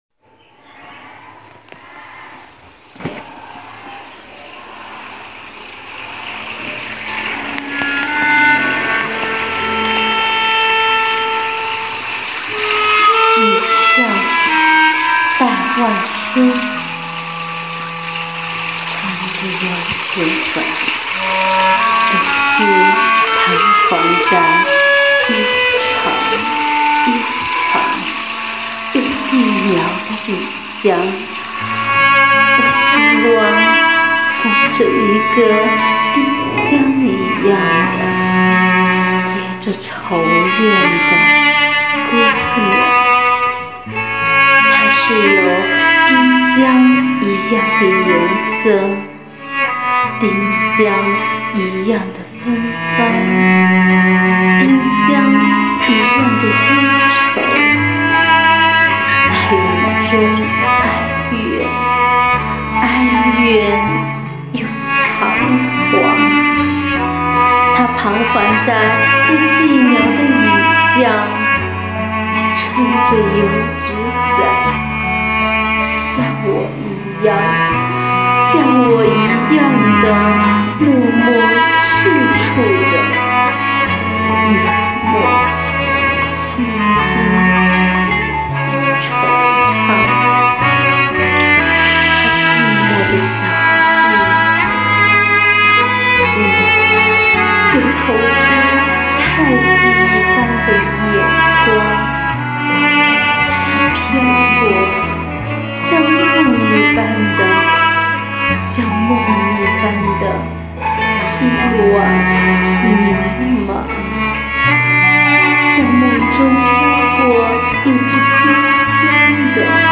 朗诵者介绍: